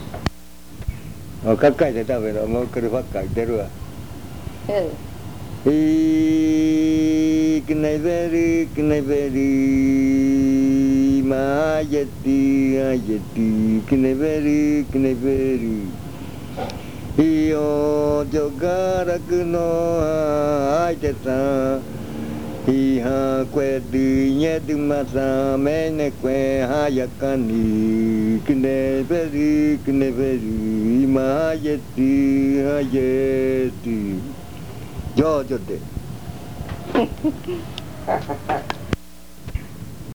Leticia, Amazonas
Recoja el chinchorro, canto de despedida.
Pick up the hammock, farewell chant.
This chant is part of the collection of chants from the Yuakɨ Murui-Muina (fruit ritual) of the Murui people